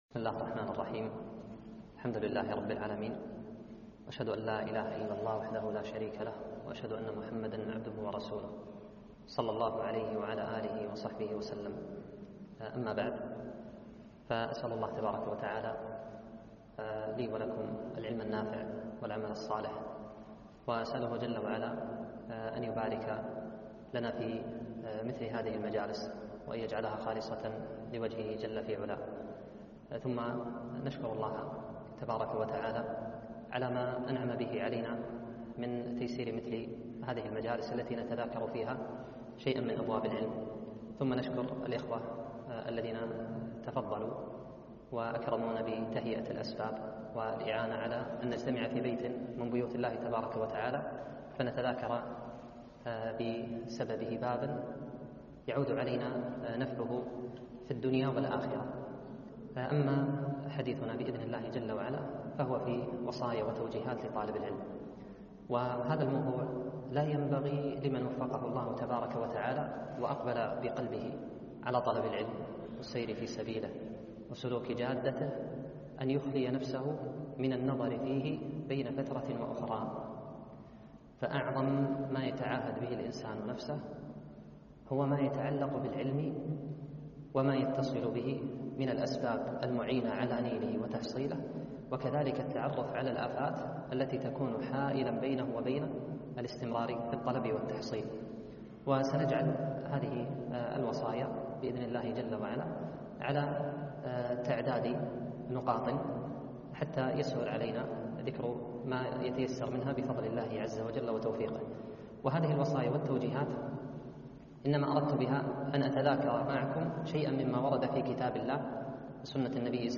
محاضرة - وصايا وتوجيهات لطلاب العلم